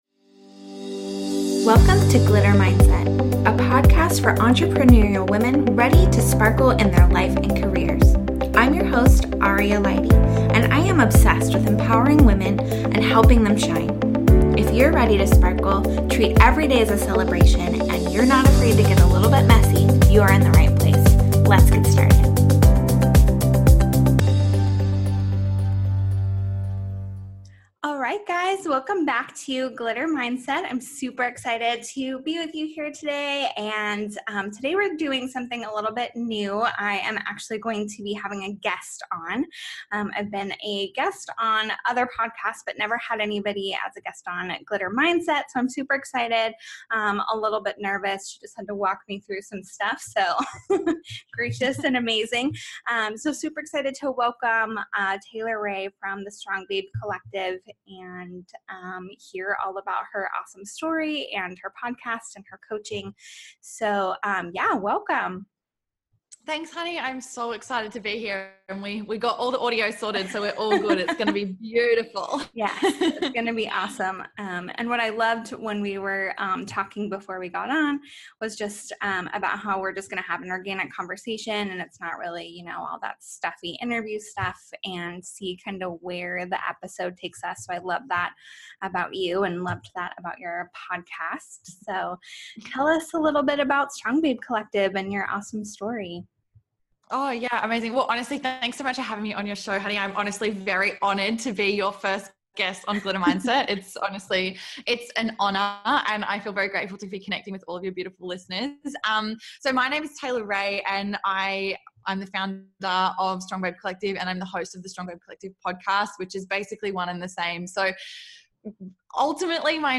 joins me in a conversation about stepping into your power. Let’s discuss aligning with your best self, preventing burnout, showing up, and owning your ultimate power.